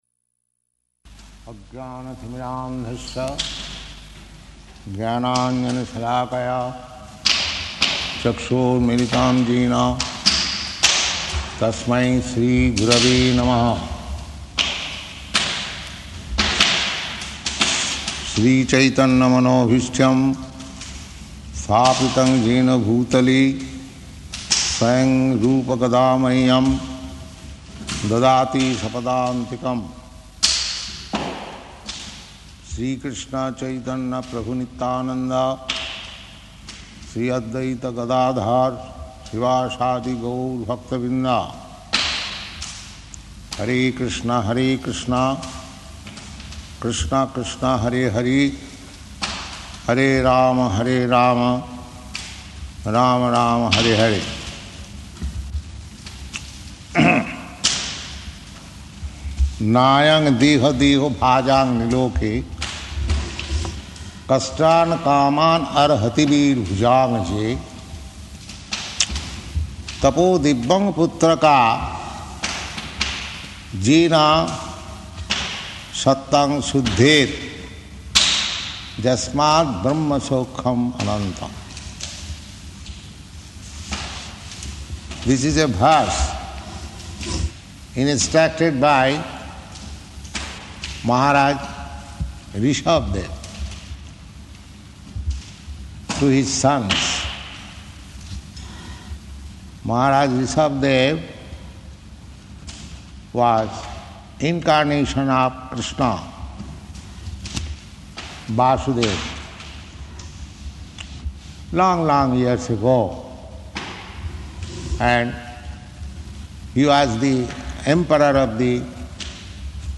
Location: London
Prabhupāda: [chants maṅgalācaraṇa ]